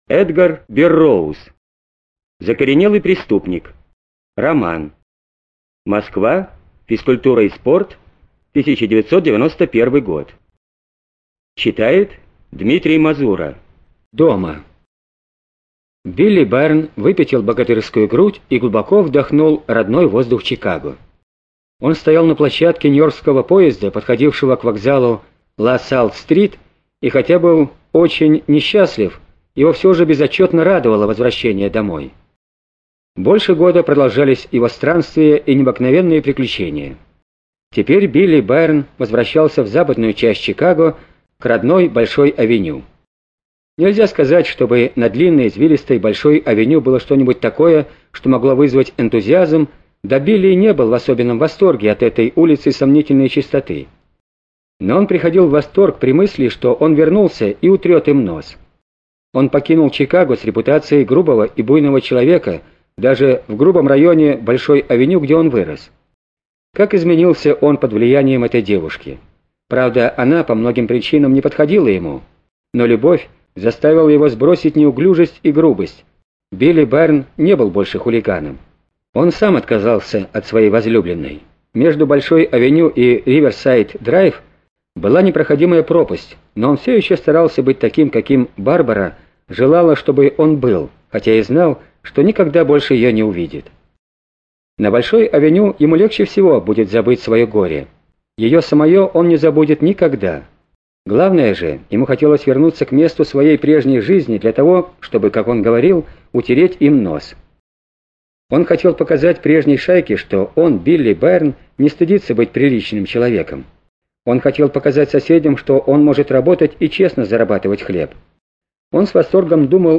Студия звукозаписизвукотэкс